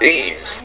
libretro/bios/Magnavox/Odyssey2/voice/EB80.WAV
O2EM voice (270), Azahar 3DS (4), EightyOne ZX81 (35),